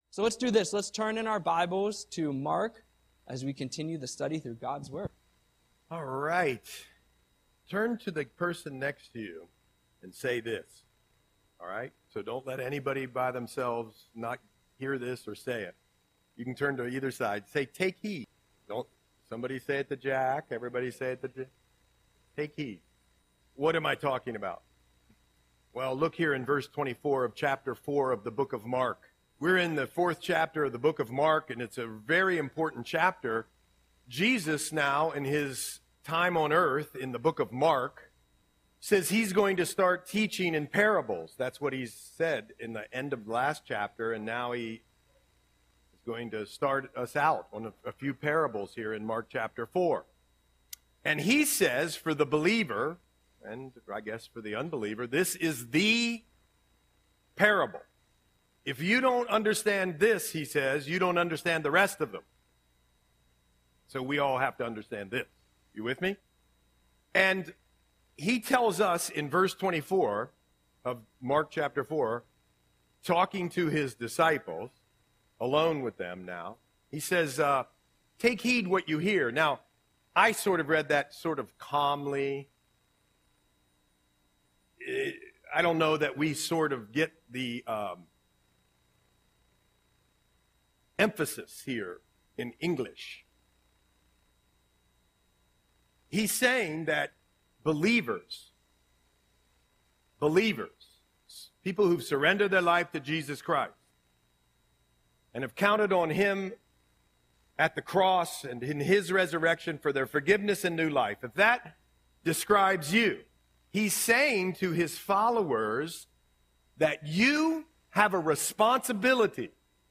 Audio Sermon - December 1, 2024